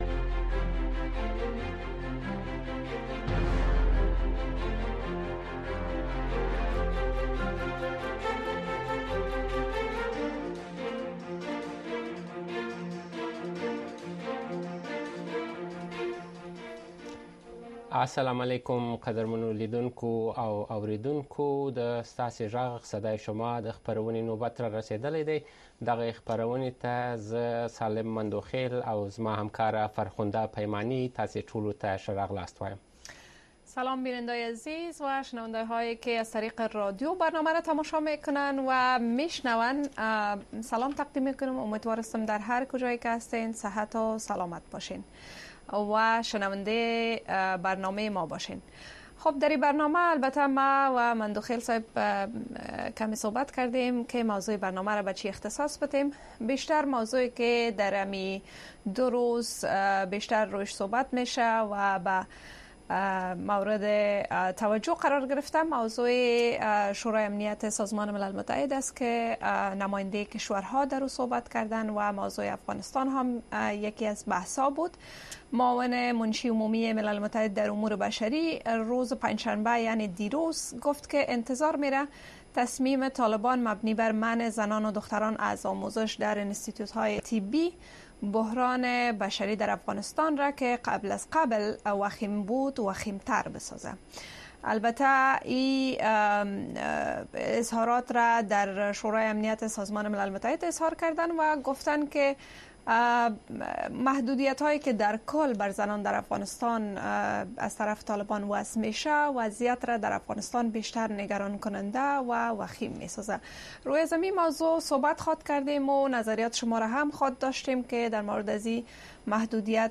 ستاسې غږ خپرونه کې د امریکا غږ د اشنا رادیو اوریدونکي په مستقیمه توګه له مونږ سره اړیکه نیسي او د خپرونې د چلوونکو او اوریدونکو سره خپل نظر، اندیښنې او شکایتونه شریکوي. دا خپرونه په ژوندۍ بڼه د افغانستان په وخت د شپې د ۹:۳۰ تر ۱۰:۳۰ بجو پورې خپریږي.